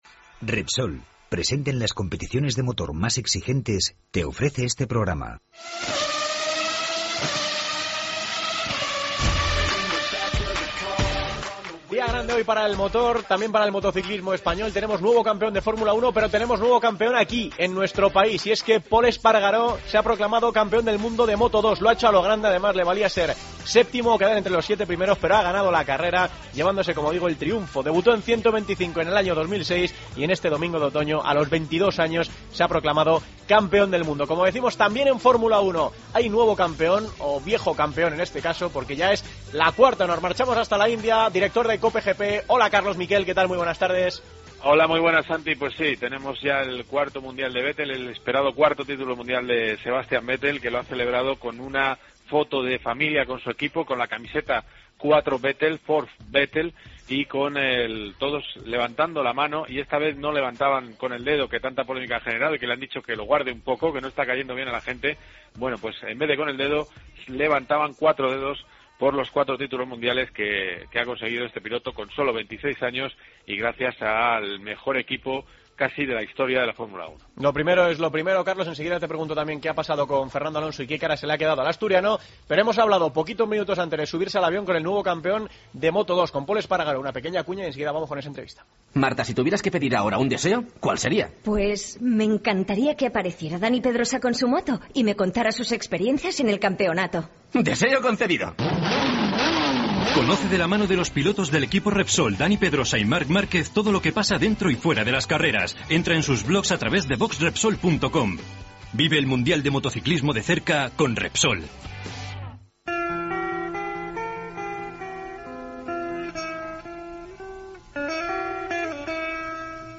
Y hablamos del Gran Premio de motociclismo en Moto2, Moto3 y MotoGP. Entrevistamos al campeón del mundo en Moto2, Pol Espargaró.